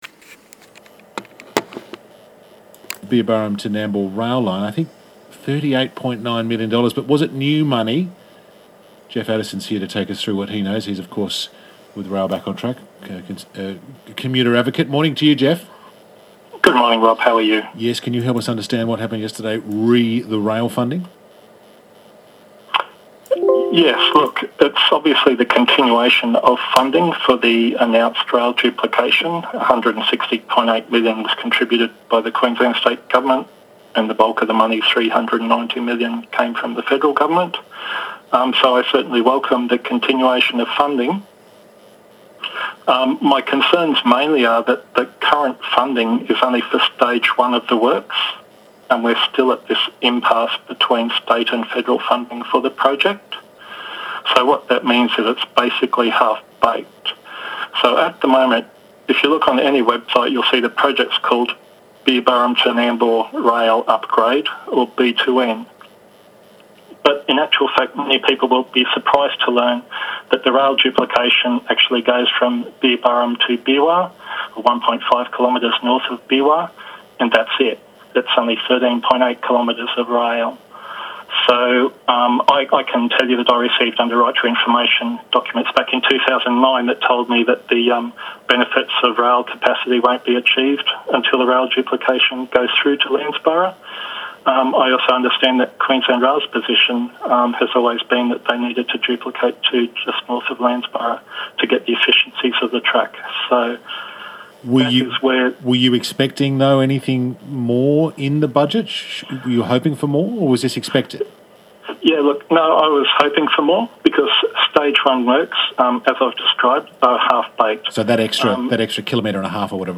Interview  2nd December 2020